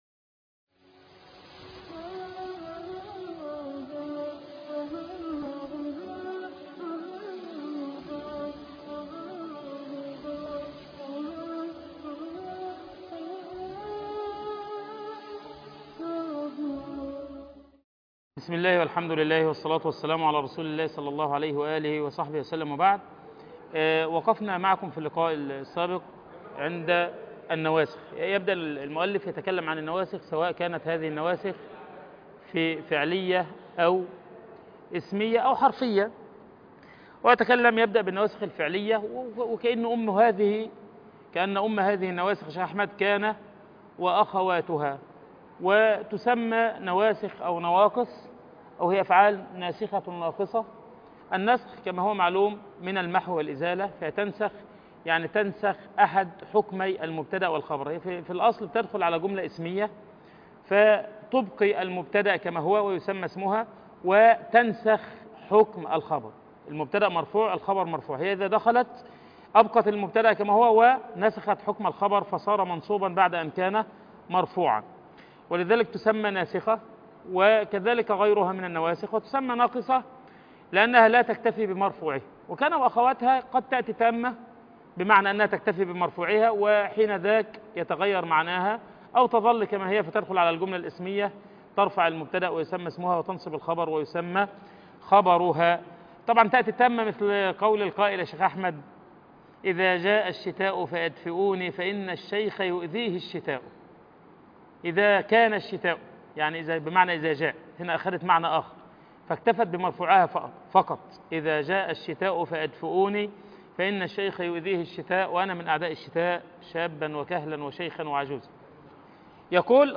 شرح متن ألفية ابن مالك - المحاضرة السابعة عشر